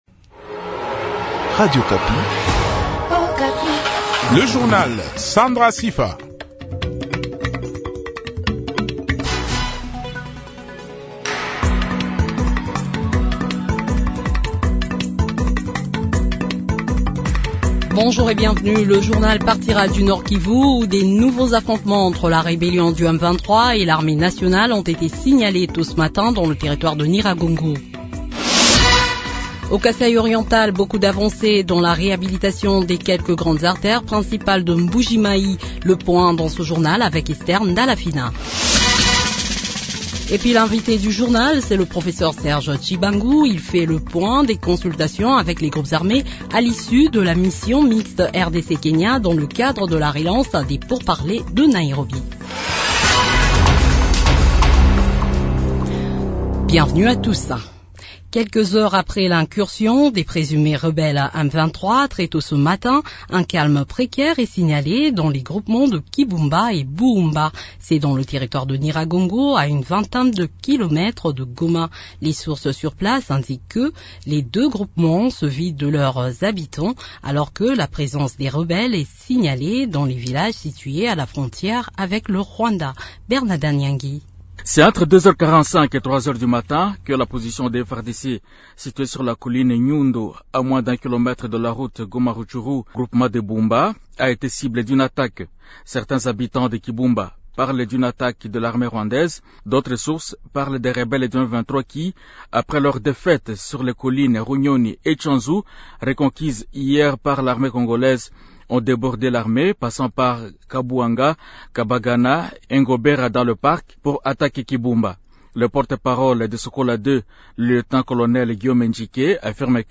Le journal de 12 heures du 24 mai 2022